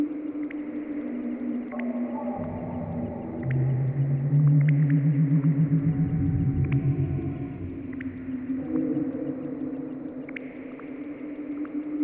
amb_loop.wav